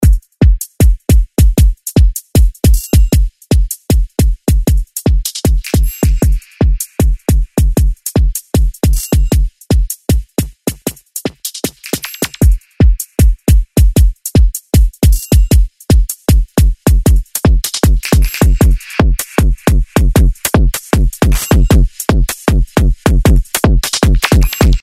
ドラムのみはこちら。